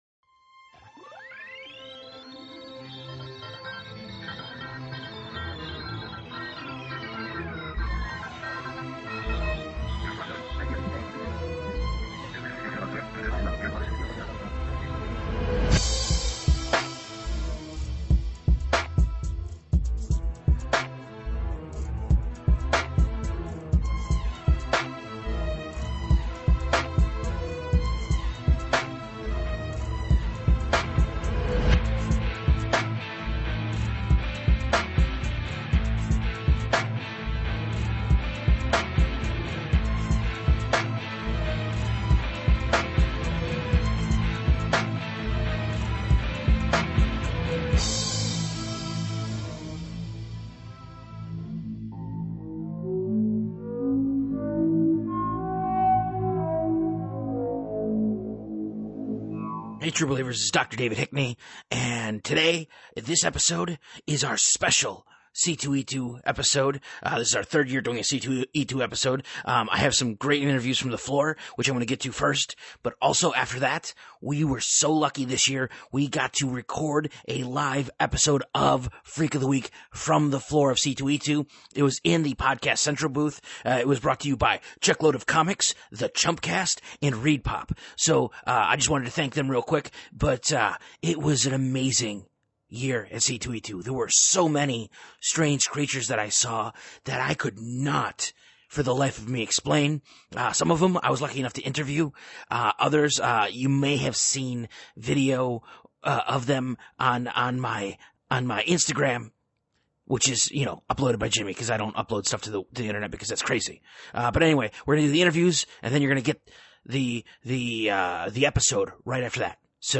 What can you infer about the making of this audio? Our annual pilgrimage to C2E2 in Chicago led to some surprising moments.